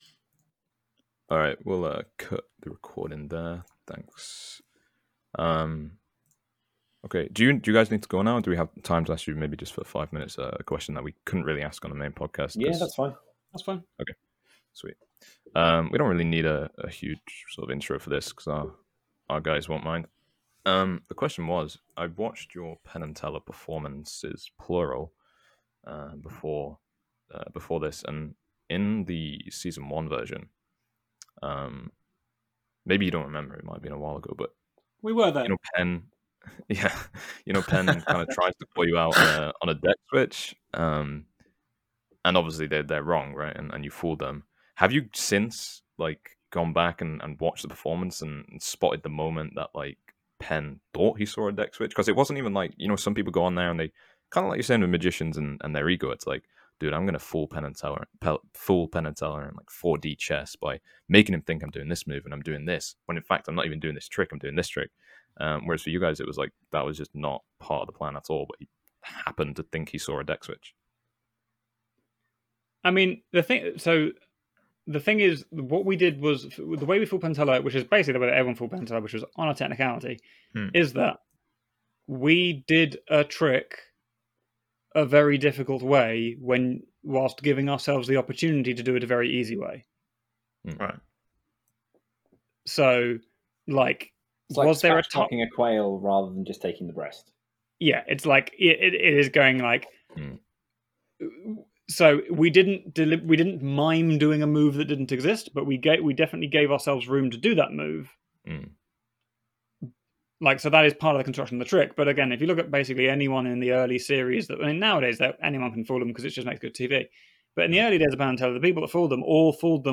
Morgan and West Exclusive Interview
So when we had Morgan and West on our podcast, we waited until we finished recording, and then couldn’t resist asking them…